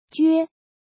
怎么读
juē jué
jue1.mp3